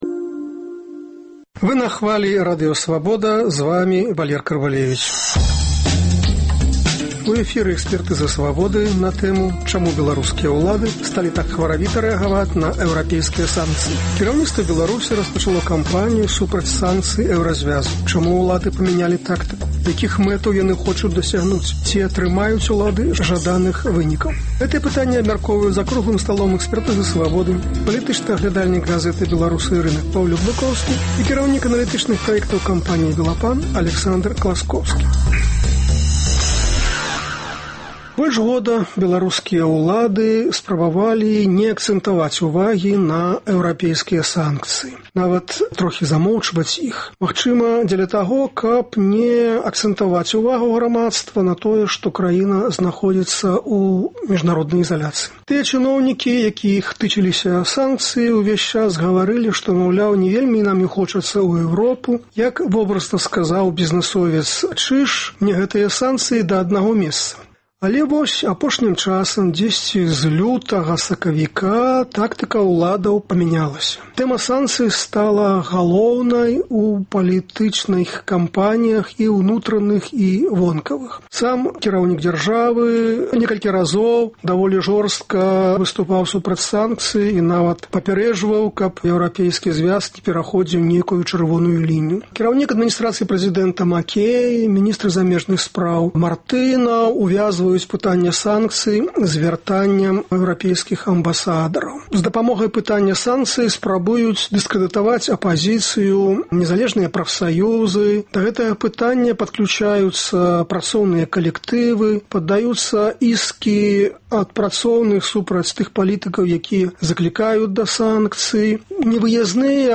Экспрэс-аналіз бягучых падзеяў. Чаму беларускія ўлады сталі так хваравіта рэагаваць на эўрапейскія санкцыі?